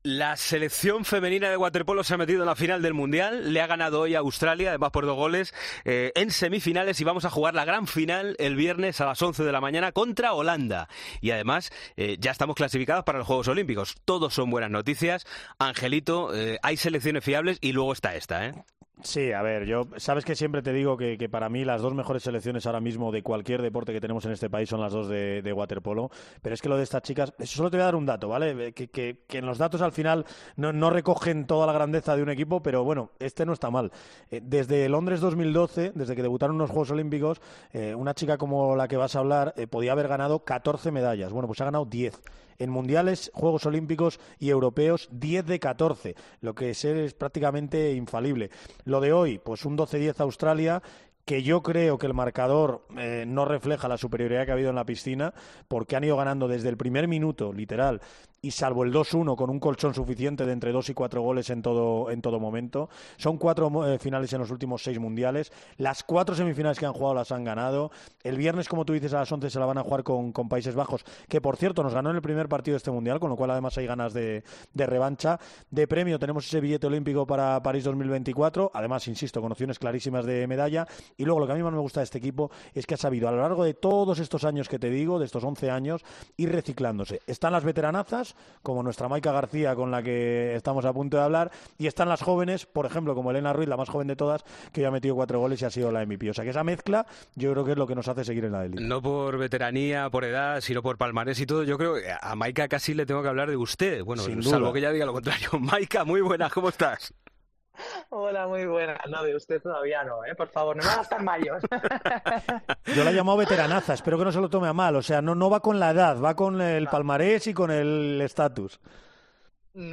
La jugadora de la Selección Española de waterpolo analizó la victoria en semis del Mundial, la clasificación para la final y para los Juegos Olímpicos.